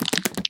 Minecraft Version Minecraft Version snapshot Latest Release | Latest Snapshot snapshot / assets / minecraft / sounds / mob / spider / step1.ogg Compare With Compare With Latest Release | Latest Snapshot
step1.ogg